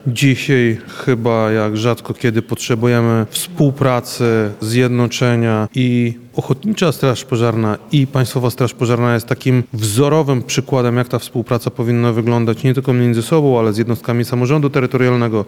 Uroczystości odbyły się w Lubelskim Urzędzie Wojewódzkim.